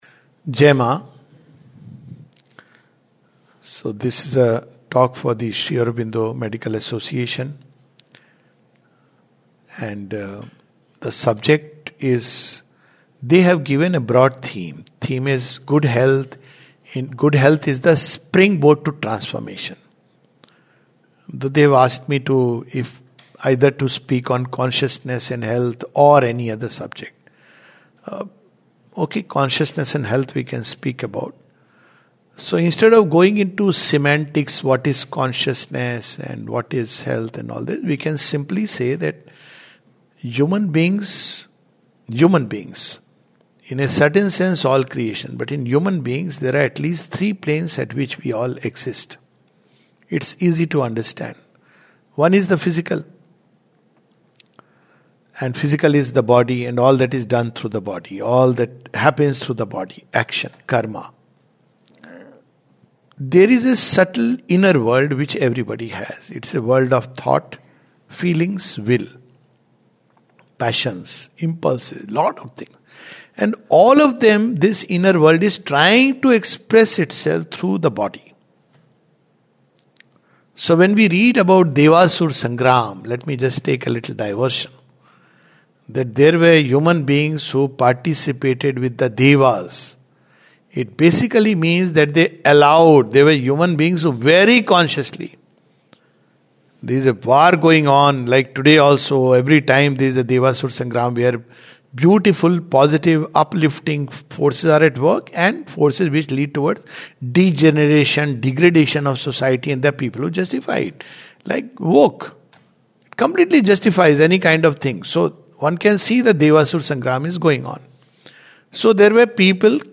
[Consciousness and Health] This is a talk for the Sri Aurobindo Medical Association, Rourkela.